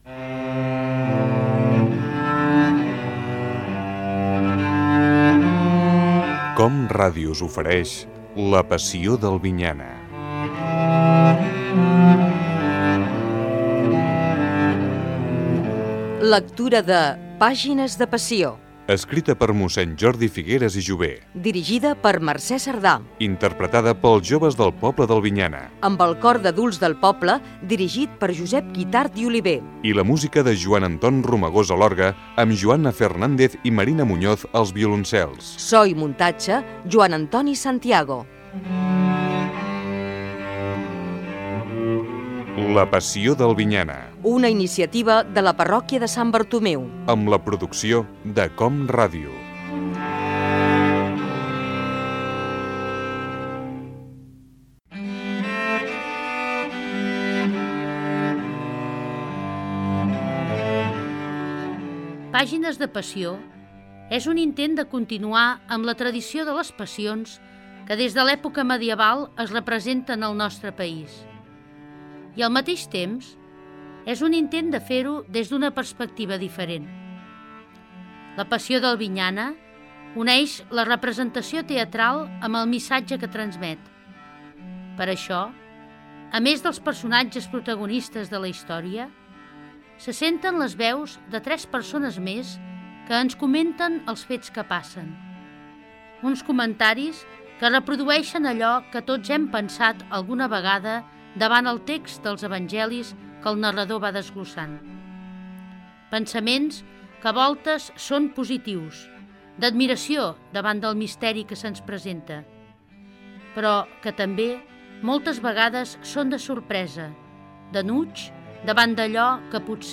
Careta del programa, explicació del què és "Pàgines de Passió"
Ficció
FM